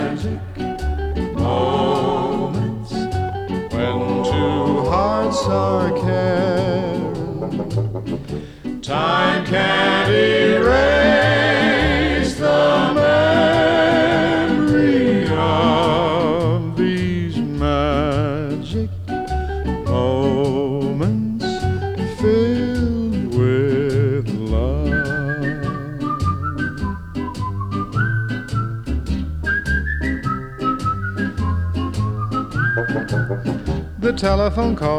# Vocal